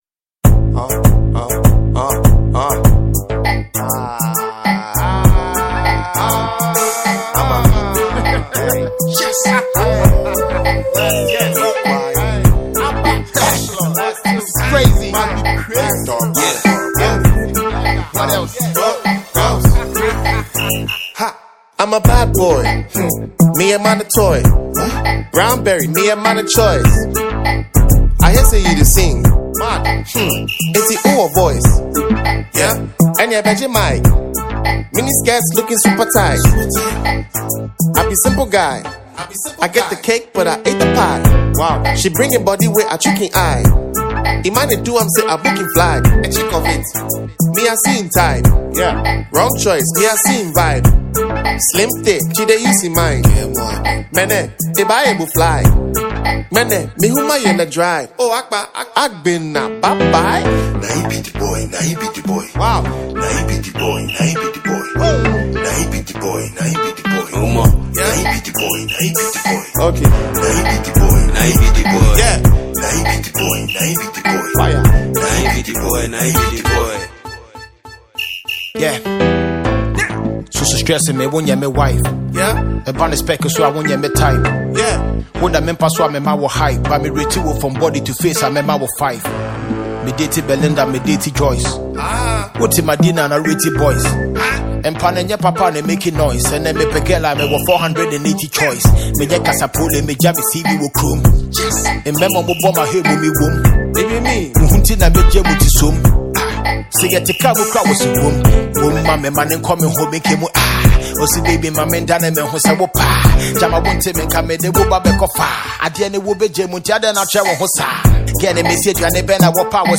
Known for shaping the sound of Ghanaian hip-hop and hiplife